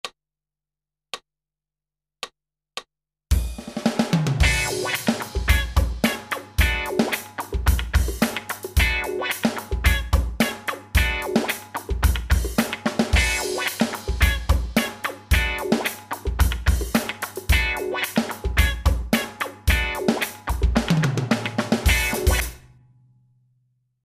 マイナスワン（ＭＰ３　376KB）
lesson1slapbannsou.mp3